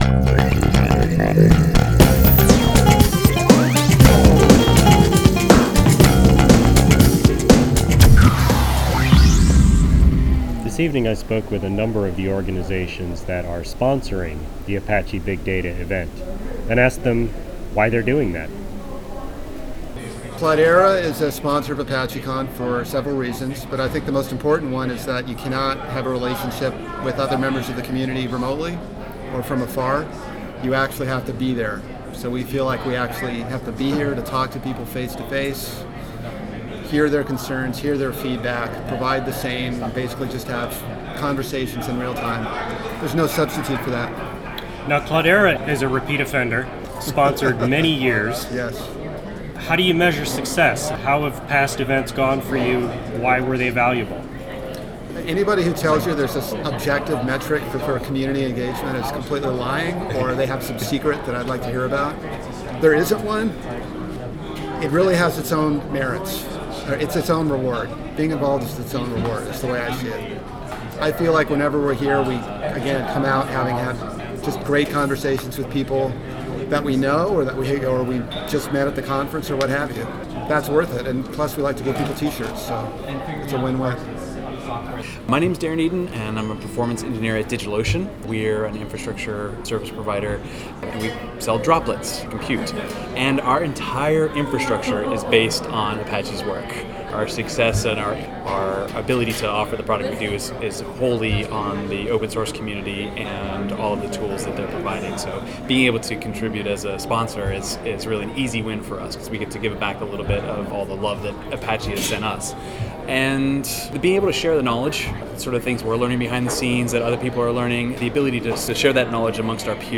I spoke with several of the sponsors at Apache Big Data this evening, and asked why they sponsor.